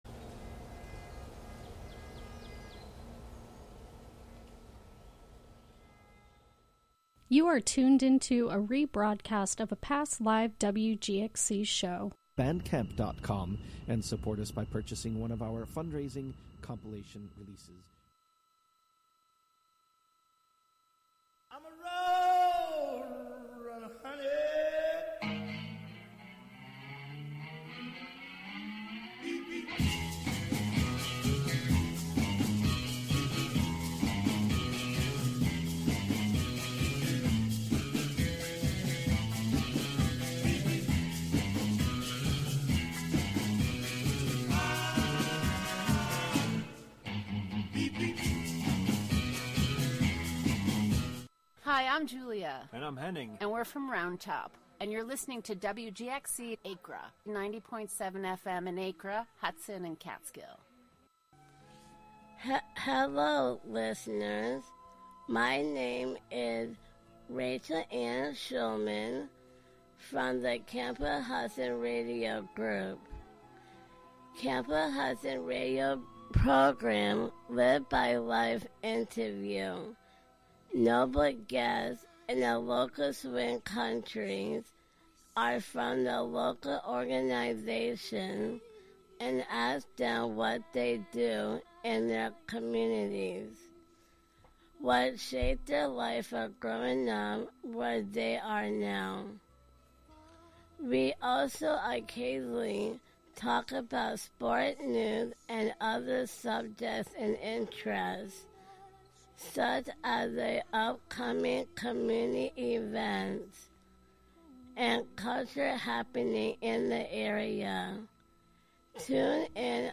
brings you sounds from raves and clubs around the world